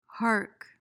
PRONUNCIATION: (hark) MEANING: verb intr.: 1.